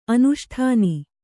♪ anuṣṭhāni